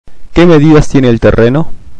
Llamaba por la casa ubicada en la calle Pavón al 3800.(Argentineans pronounce ll and y as sh)